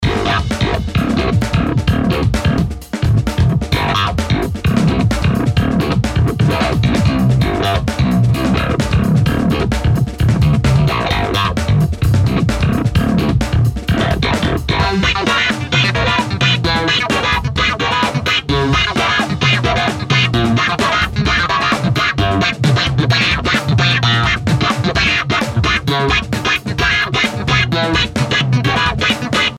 Das Vibranet liefert einen satten, markanten Bass und zeigt sich auch in den Höhen bissig – wenn man den Amp auf „crunch“ eingestellt hat.
Hier war wieder Toontracks EZdrummer, EZX Funk sowie ein Pattern aus EZkeys Funk MIDI beteiligt.